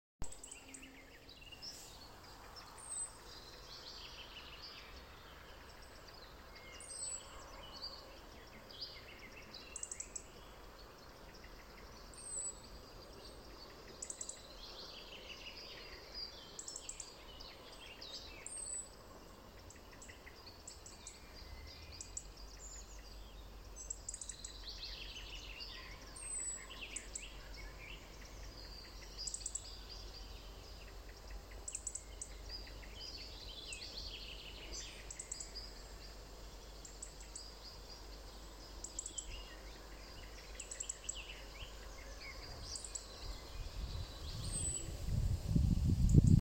зарянка, Erithacus rubecula
Ziņotāja saglabāts vietas nosaukumsDaļēji izcirsts mežs
СтатусПоёт